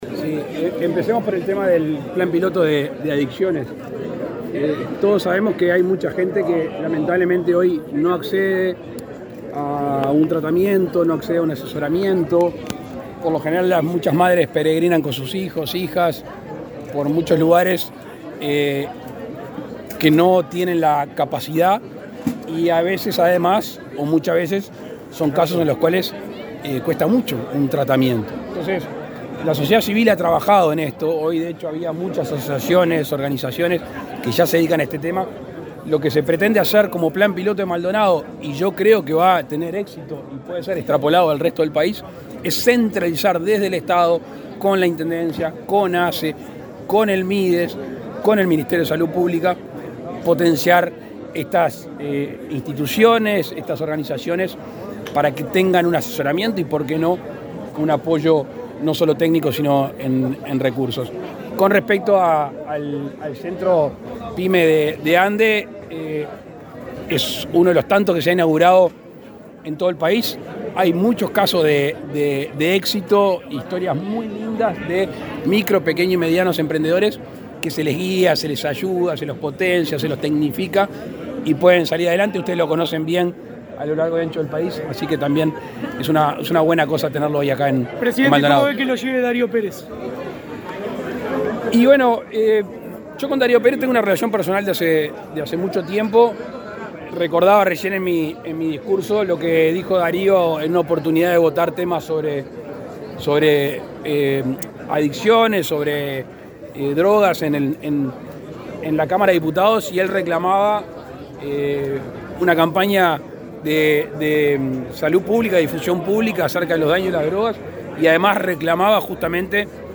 Declaraciones del presidente Lacalle Pou a la prensa
Declaraciones del presidente Lacalle Pou a la prensa 30/09/2022 Compartir Facebook X Copiar enlace WhatsApp LinkedIn La Intendencia de Maldonado y ASSE implementan un plan piloto de prevención y tratamiento de adicciones e instalaron un centro a tales fines. El acto inaugural fue encabezado por el presidente Luis Lacalle Pou. Luego, el mandatario contestó preguntas a periodistas.